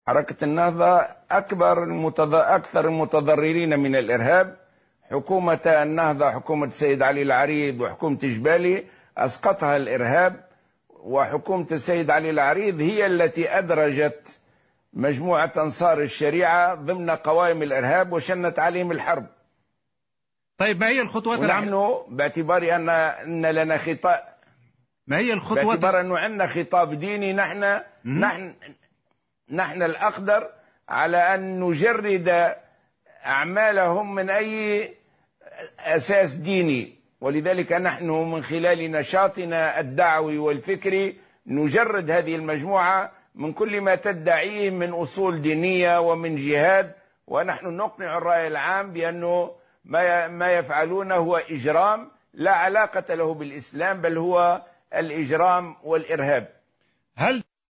Le président du mouvement Ennahdha, Rached Ghannouchi, a affirmé, lors d’une interview accordée aujourd’hui à la chaine France 24, que mouvement Ennahdha est la première victime du terrorisme.